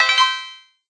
chime_3.ogg